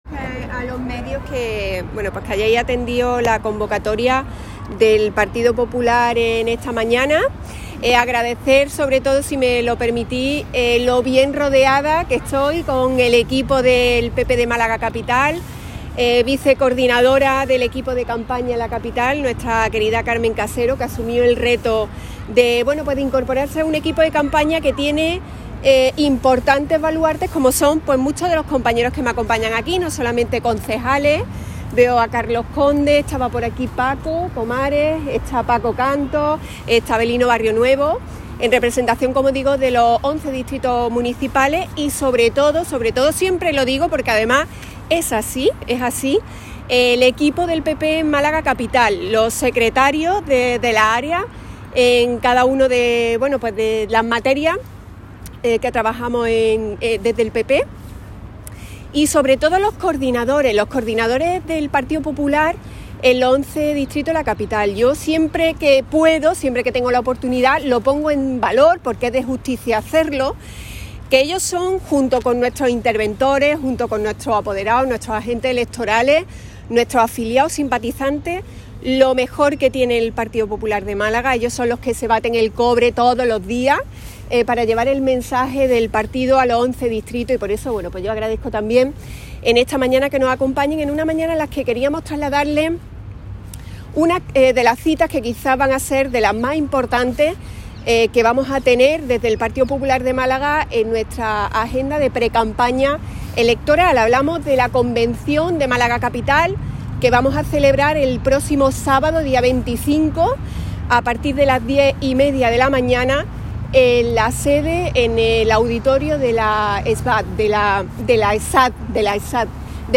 De la Torre, tras ser cuestionado por los periodistas ha asegurado que es «importante» la afición al basquet de Málaga, «y sobre todo merecía esas alegrías por la forma extraordinaria de comportarse. Un orgullo para la ciudad».